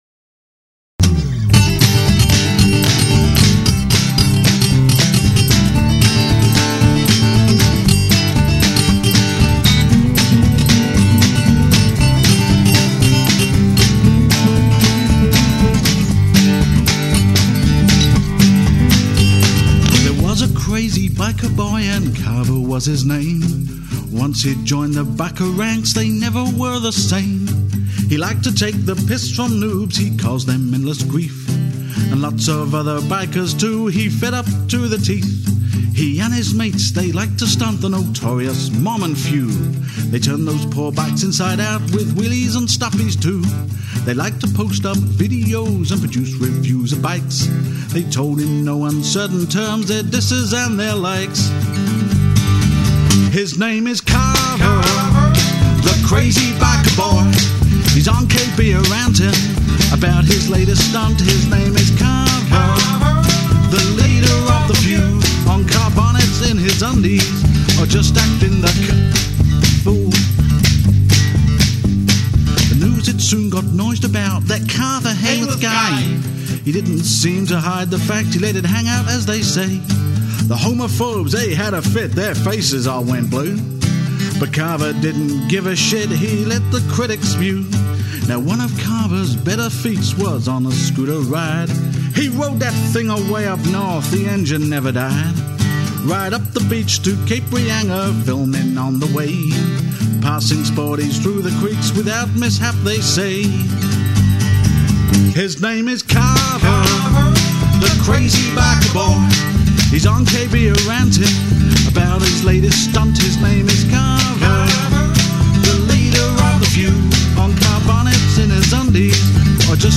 Music and Chorus